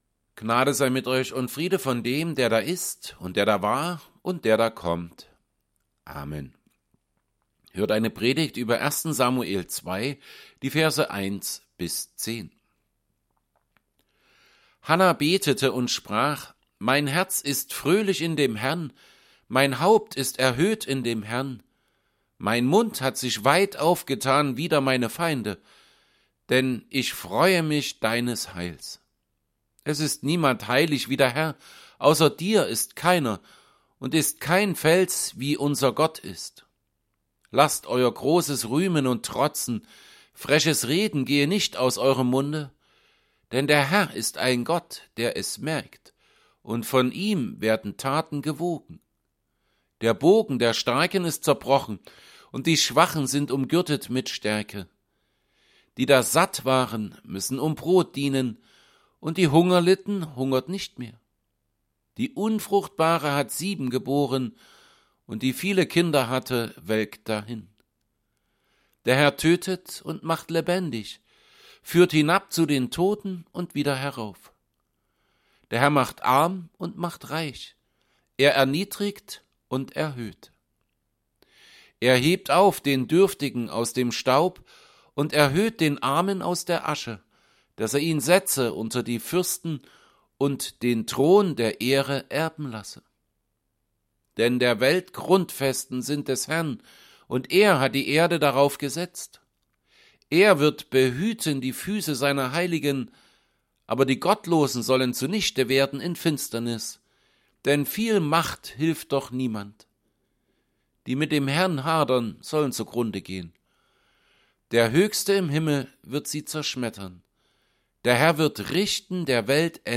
Predigt_zu_1_Samuel_2_1b10.mp3